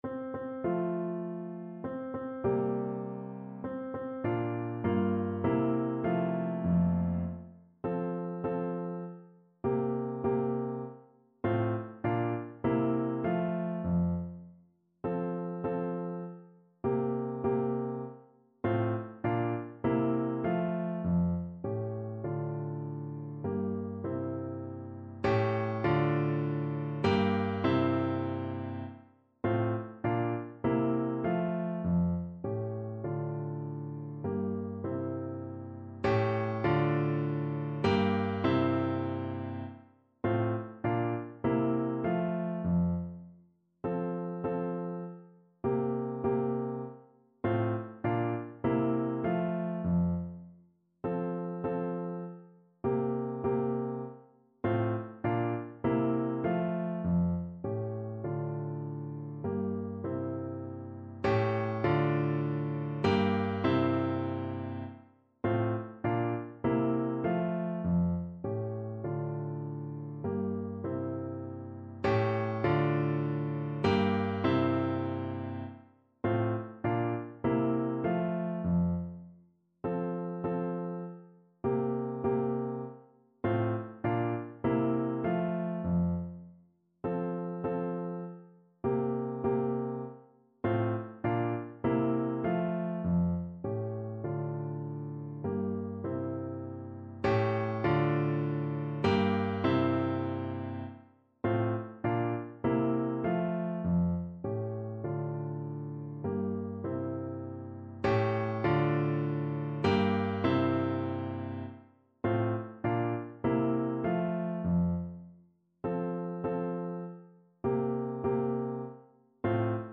kolęda: W żłobie leży (na klarnet i fortepian)
Symulacja akompaniamentu